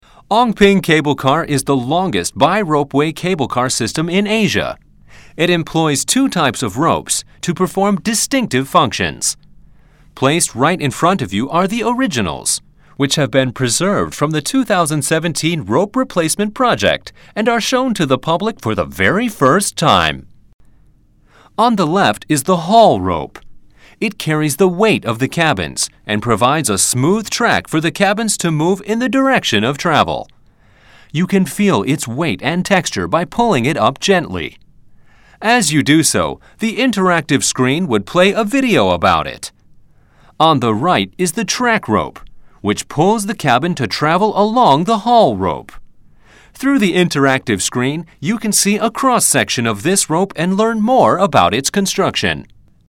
Cable Car Discovery Centre Audio Guide (English)